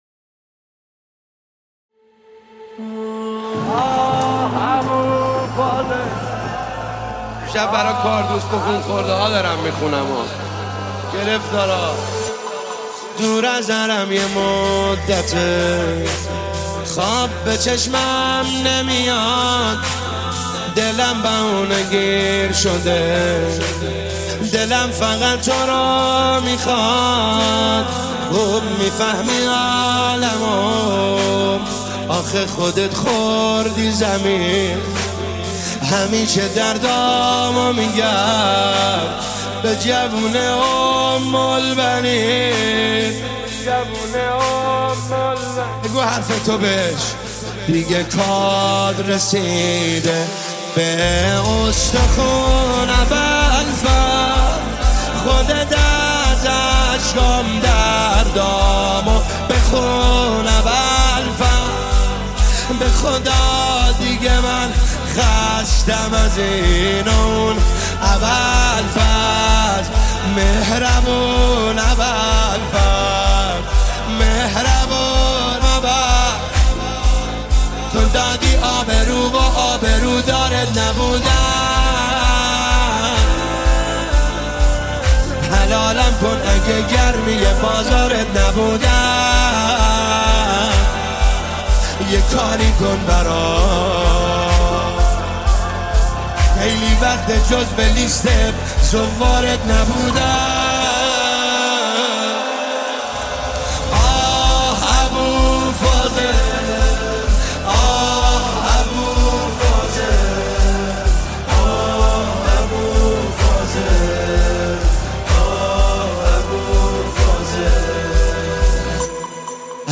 دانلود نماهنگ دلنشین
مداحی